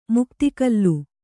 ♪ mukti kallu